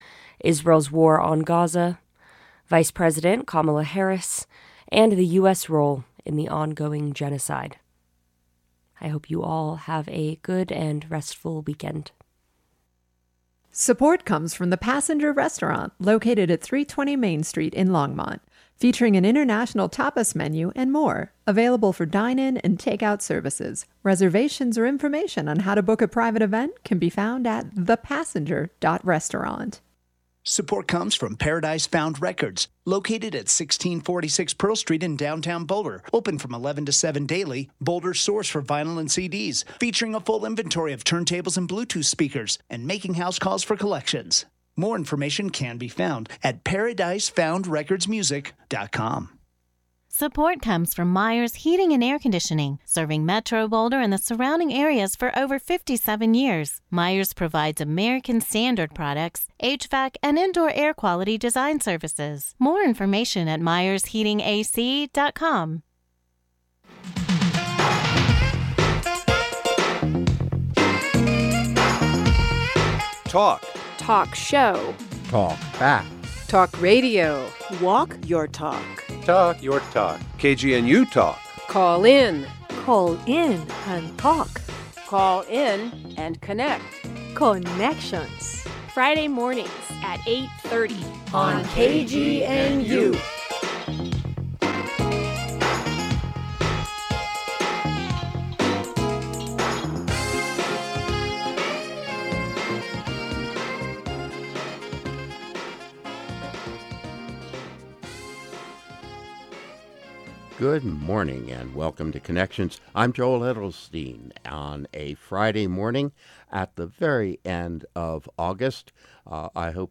Note on the program.: I was on this program by phone; half way through it – it lasts an hour – my connection was cut off and I wasn’t able to reconnect to the station until a few minutes before the end …